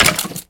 Minecraft Version Minecraft Version latest Latest Release | Latest Snapshot latest / assets / minecraft / sounds / mob / skeleton / hurt4.ogg Compare With Compare With Latest Release | Latest Snapshot
hurt4.ogg